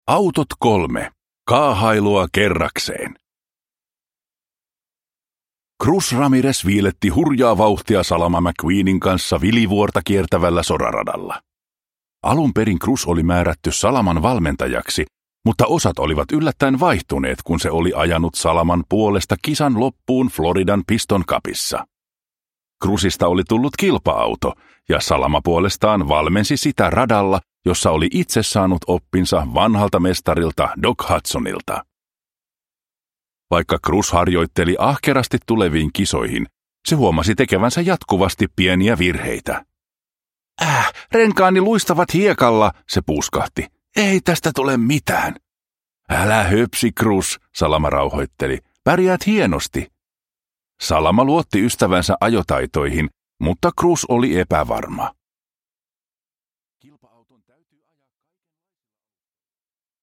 Pixar Autot. Kaahailua kerrakseen – Ljudbok – Laddas ner